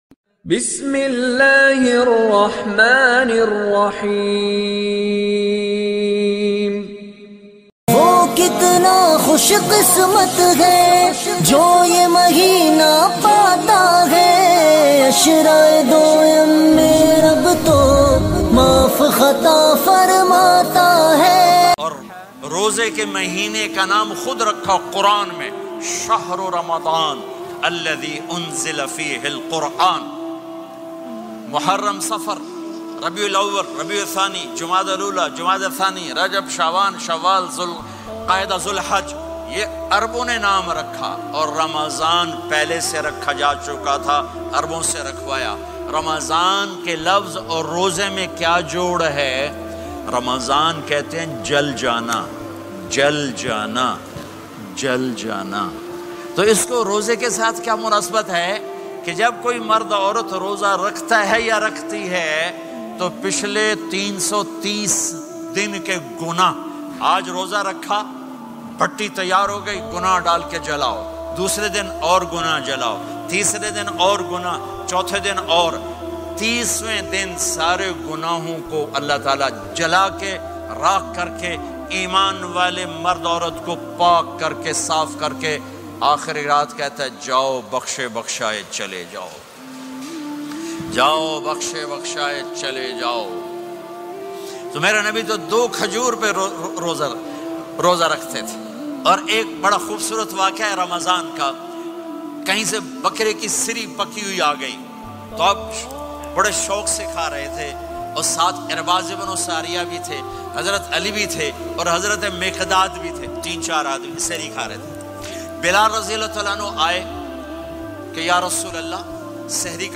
Nabi Ke Ramzan Ke Waqiat Beautiful incident of ramzan Maulana Tariq Jameel Bayan MP3 Download.